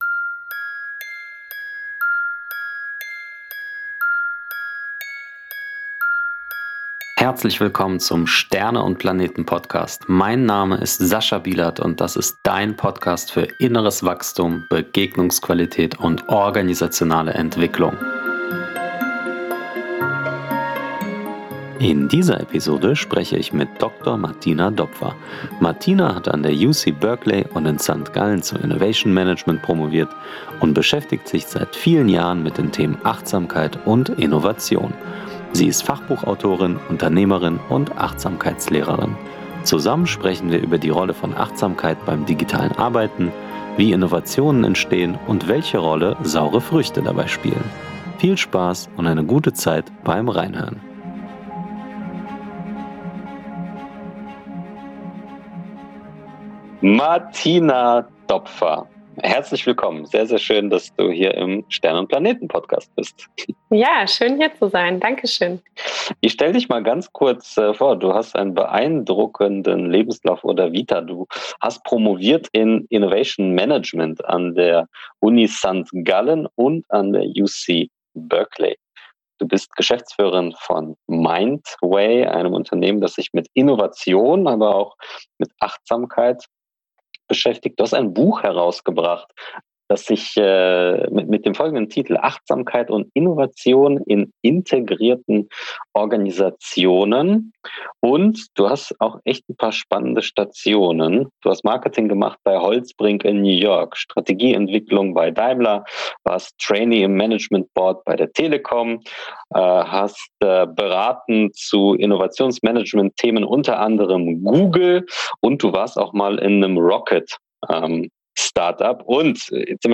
Gemeinsam schauen wir darauf, wie Achtsamkeit uns sowohl für unsere digitalen Räume als auch Innovationsbestrebungen helfen kann. Besonders beleuchten wir welche Techniken sich im Umgang mit virtuellen Treffen bewährt haben und wie wir "Mindfulness" als Voraussetzung für "Einfach Machen" nutzen können. Als Bonus gibt es eine Mini-Meditationsreise